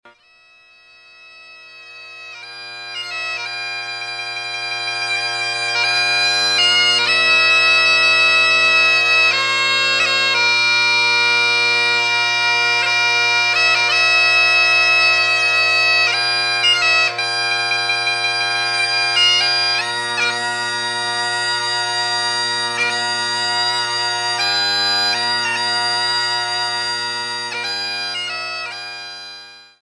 La Bagpipe